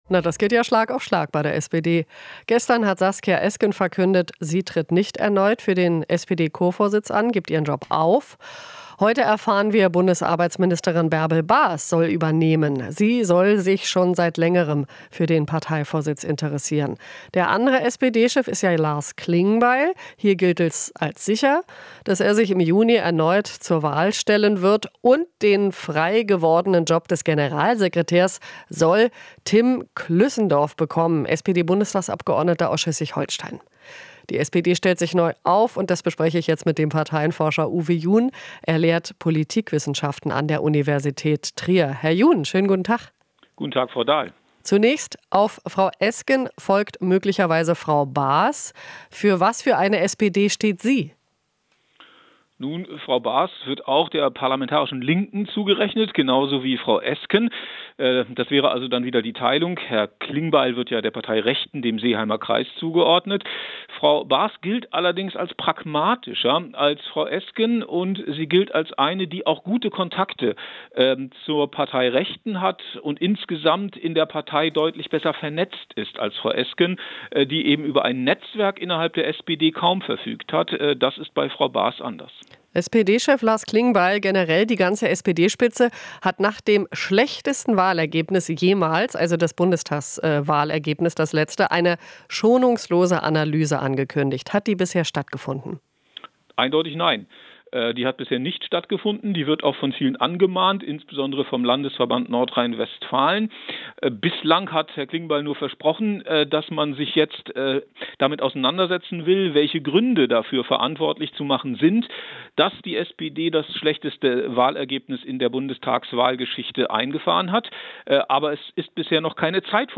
Interview - Großes Stühlerücken: Die SPD-Parteispitze stellt sich neu auf